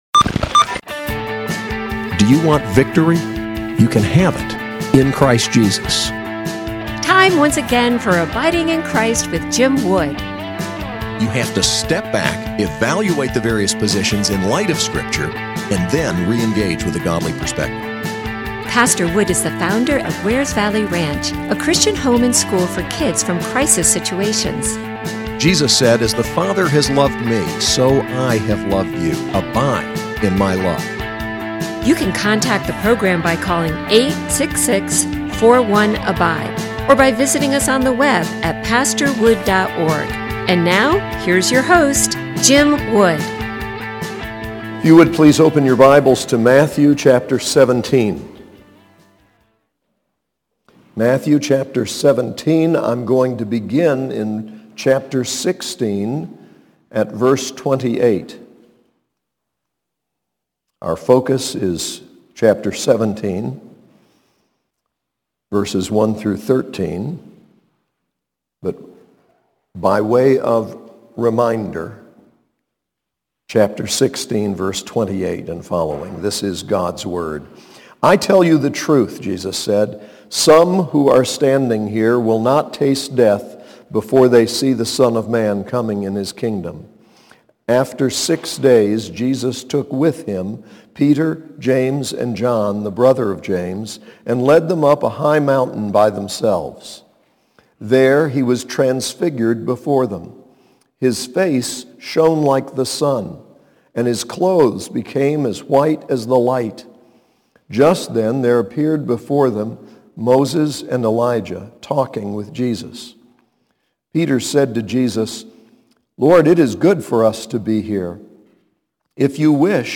SAS Chapel: Matthew 17:1-13